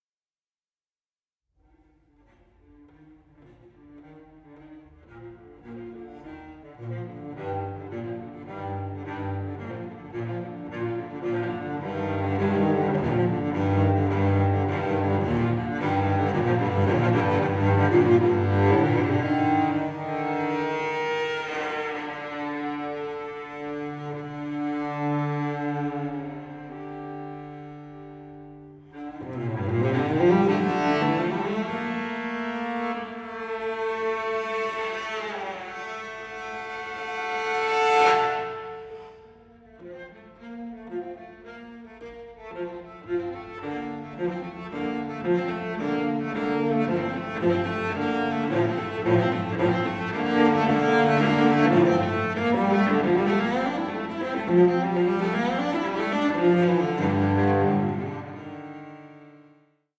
for solo cello